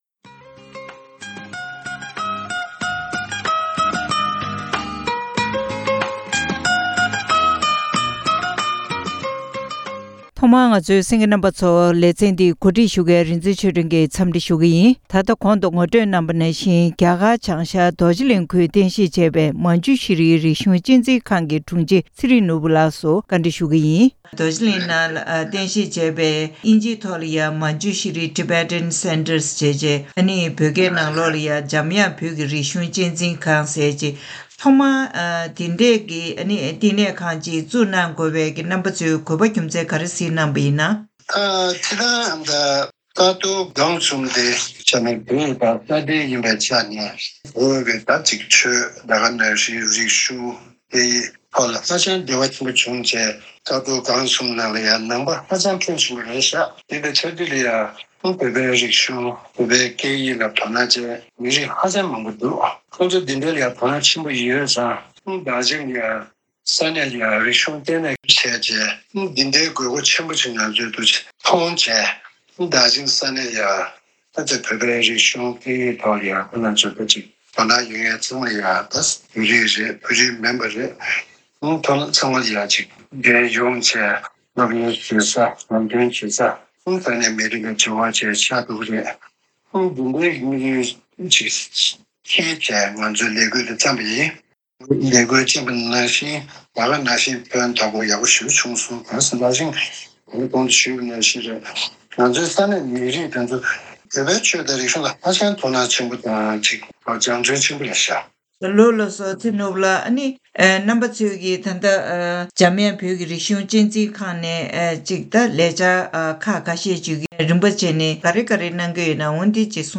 བཀའ་འདྲི་ཞུས་པ